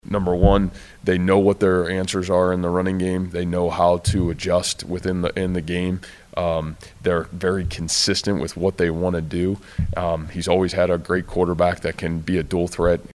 That’s Cyclone coach Matt Campbell who says their ground game will be difficult to stop.